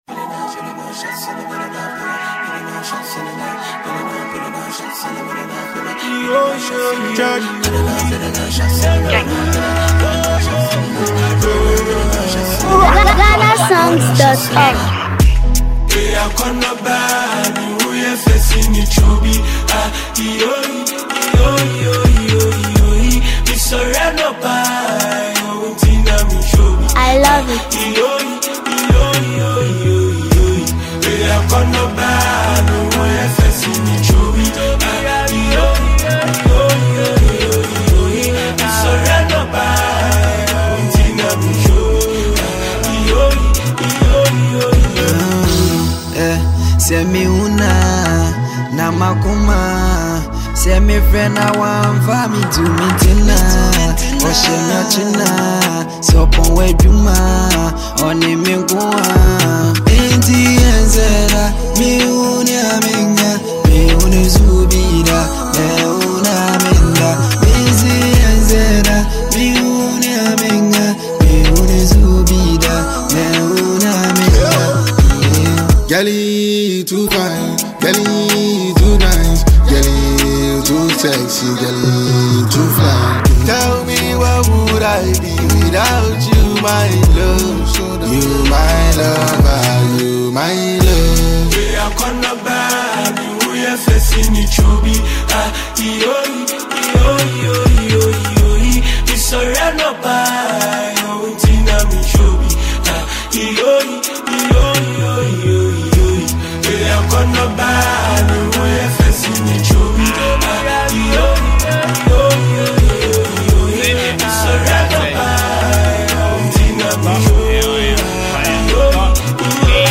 • Genre: Asakaa / Drill / Afro-Urban